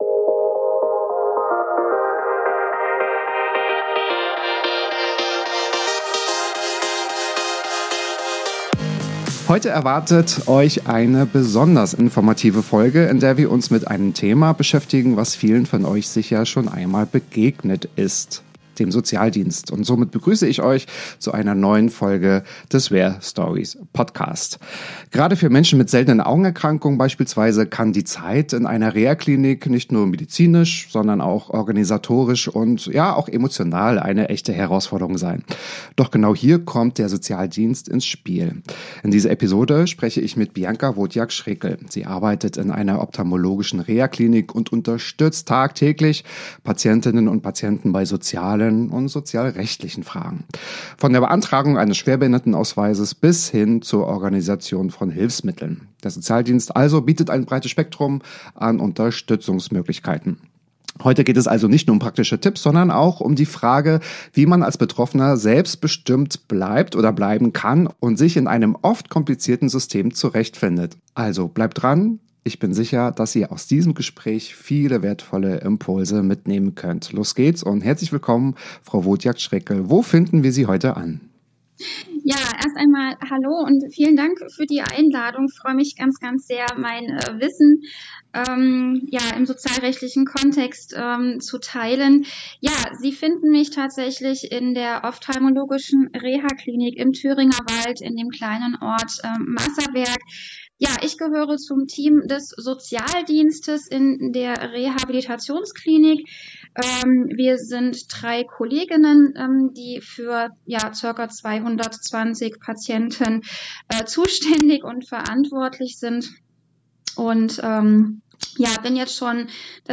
Wir erfahren, welche Herausforderungen Betroffene von seltenen Augenerkrankungen im Alltag bewältigen müssen und welche Anlaufstellen weiterhelfen. Ein Gespräch voller praktischer Tipps, einfühlsamer Ratschläge und wichtiger Informationen für alle, die sich im Dschungel des Sozialrechts besser zurechtfinden möchten.